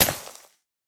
Minecraft Version Minecraft Version 1.21.5 Latest Release | Latest Snapshot 1.21.5 / assets / minecraft / sounds / block / suspicious_sand / break3.ogg Compare With Compare With Latest Release | Latest Snapshot
break3.ogg